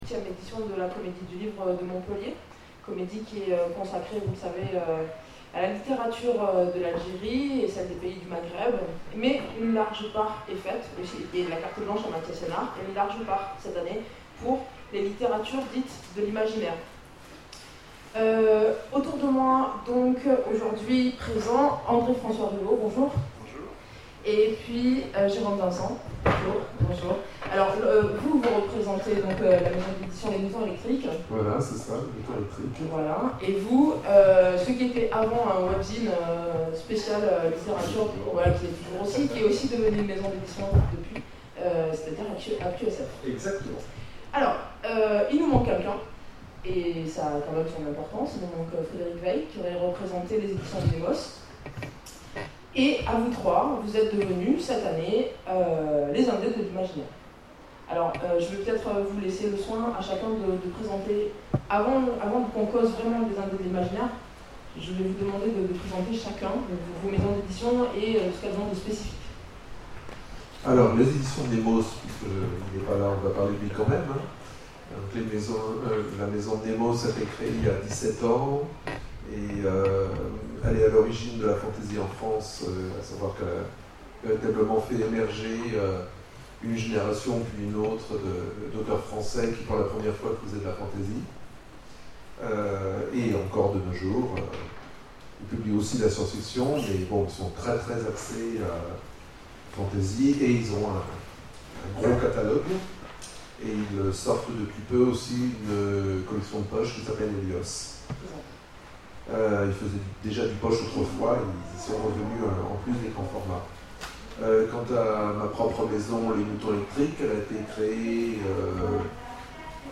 La Comédie du livre 2013 : Conférence sur les Indés de l'Imaginaire